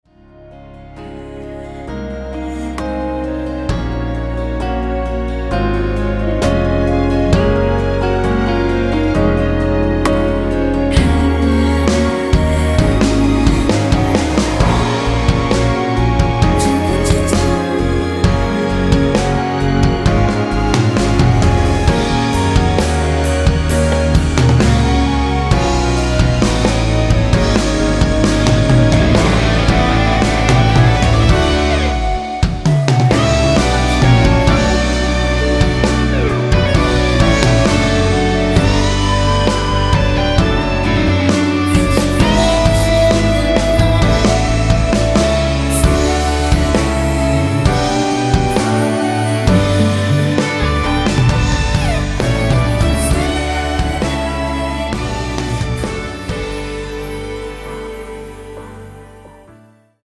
원키(1절앞+후렴)으로 진행되는 멜로디와 코러스 포함된 MR입니다.(미리듣기 확인)
Db
앞부분30초, 뒷부분30초씩 편집해서 올려 드리고 있습니다.